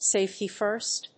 アクセントsáfety‐fírst